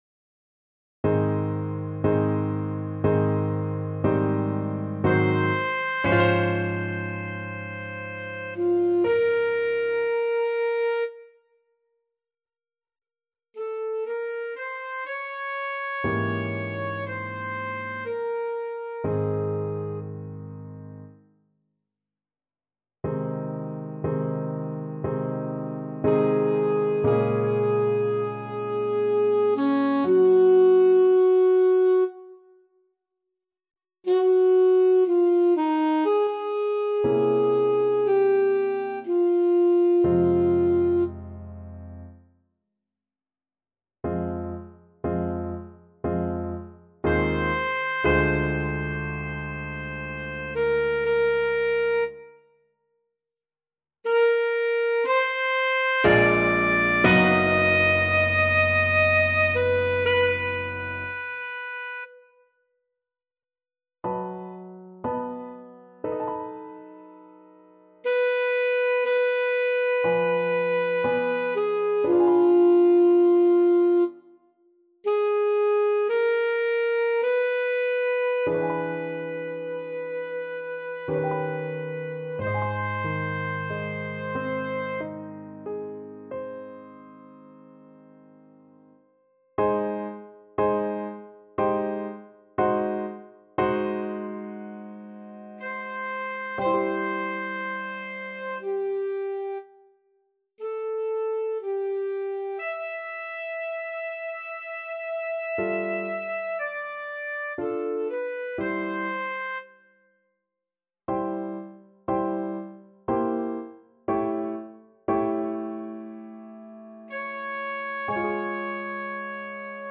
Alto Saxophone version
Alto Saxophone
3/4 (View more 3/4 Music)
~ = 60 Langsam, leidenschaftlich
Classical (View more Classical Saxophone Music)